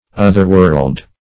otherworld - definition of otherworld - synonyms, pronunciation, spelling from Free Dictionary